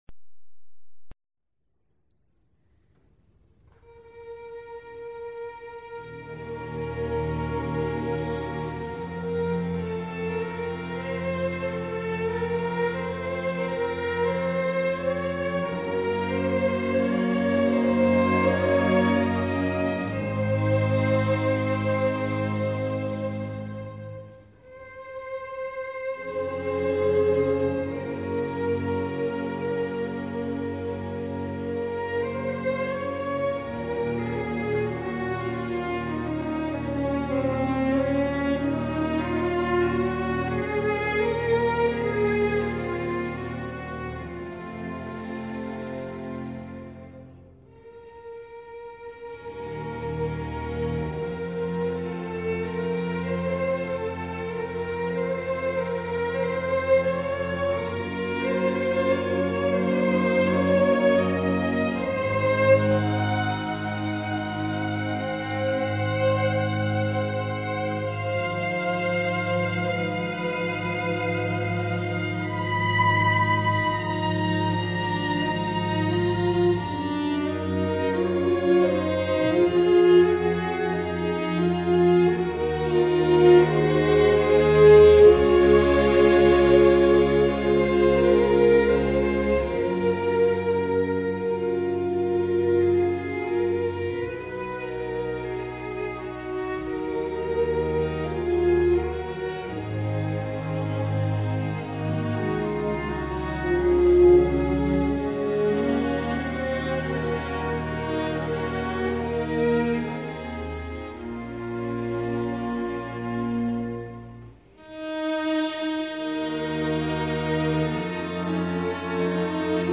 Genres: Classical Music
Tempo: slow